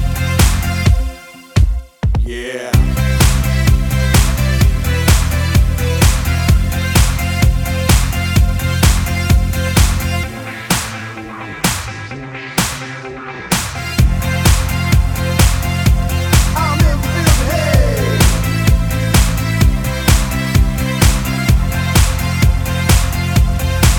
For Duet Dance 3:53 Buy £1.50